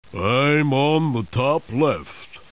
1 channel
00203_Sound_ELEPHANT.mp3